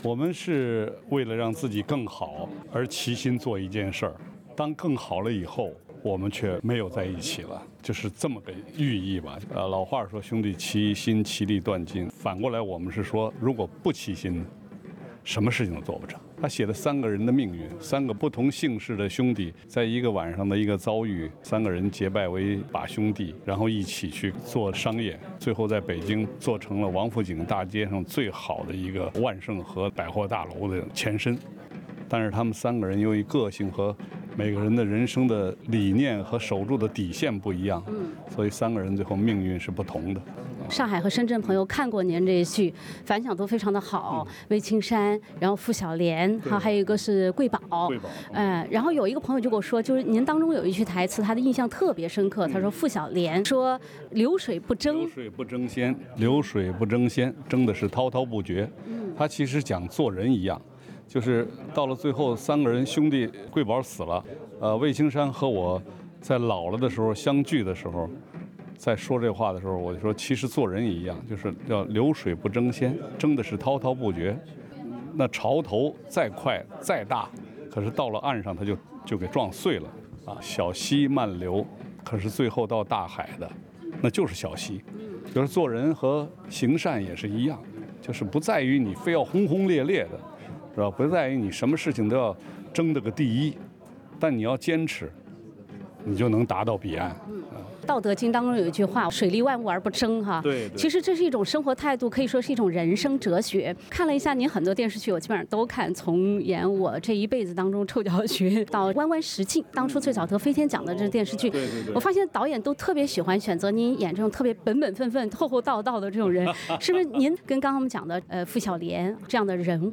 专访张国立---“铁三角”首次集体亮相澳洲